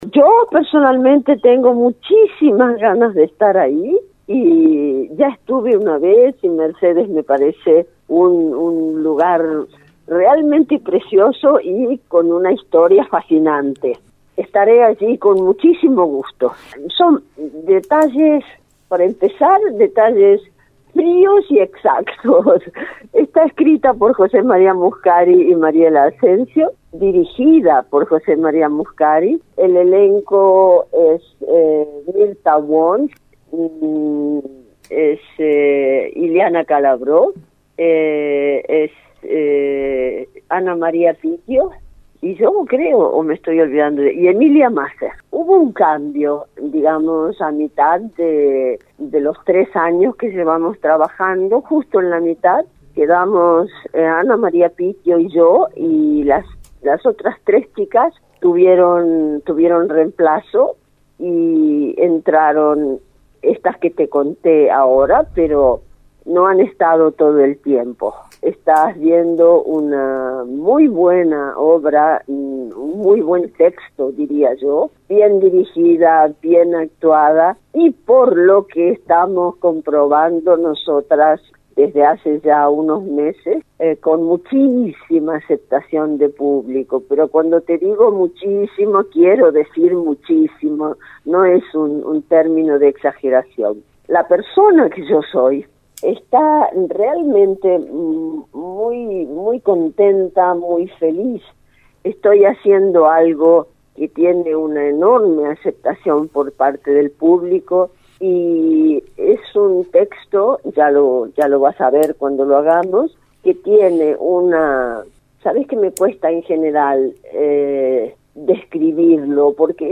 LEONOR BENEDETTO EN RADIO UNIVERSO